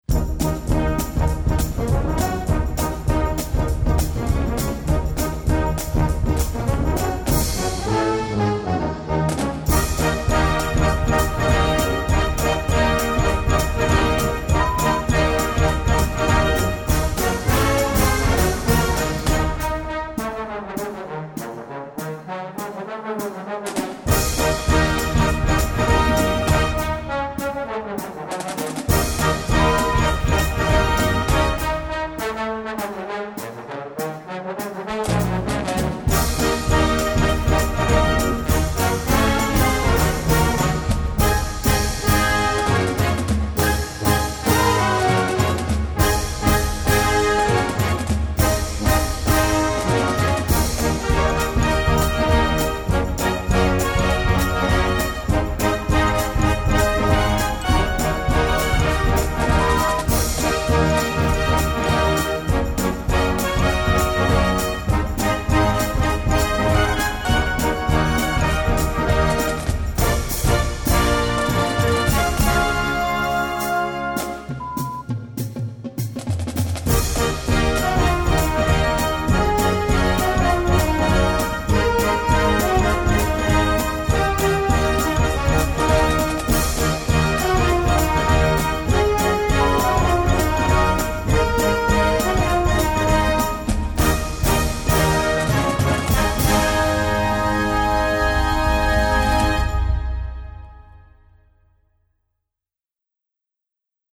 Gattung: Moderner Einzeltitel
B Besetzung: Blasorchester Tonprobe